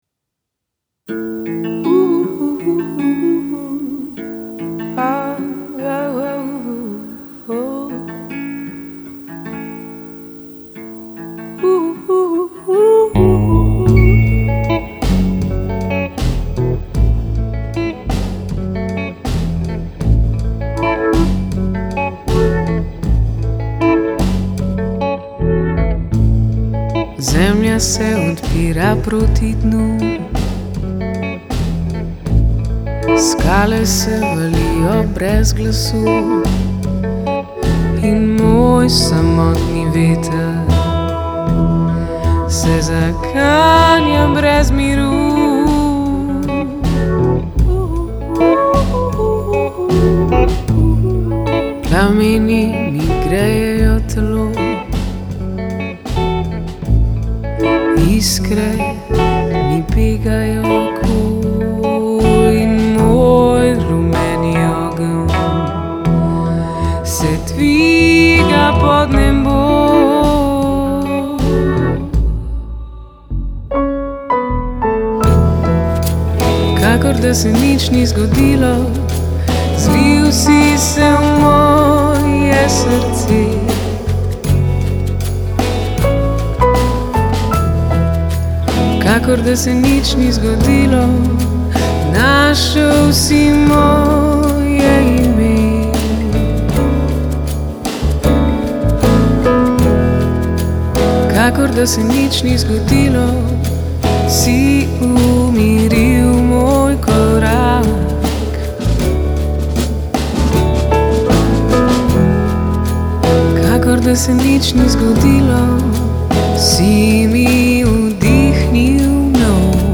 pevka